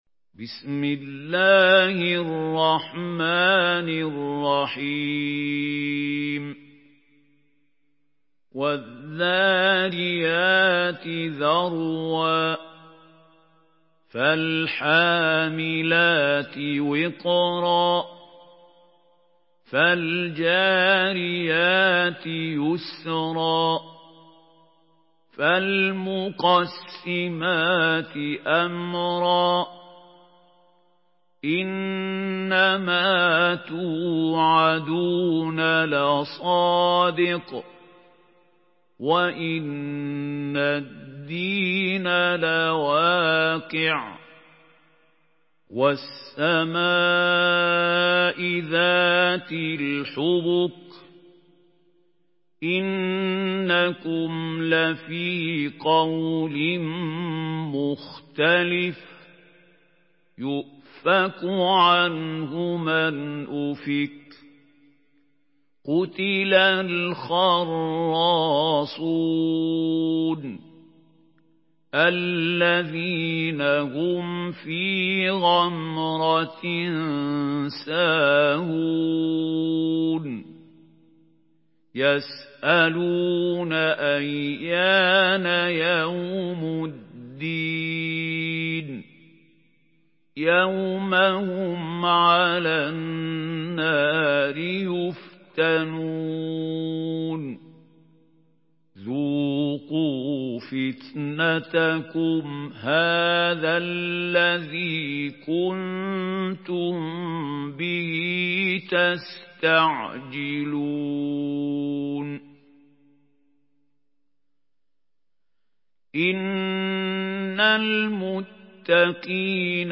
Sourate Ad-Dariyat MP3 à la voix de Mahmoud Khalil Al-Hussary par la narration Hafs
Une récitation touchante et belle des versets coraniques par la narration Hafs An Asim.
Murattal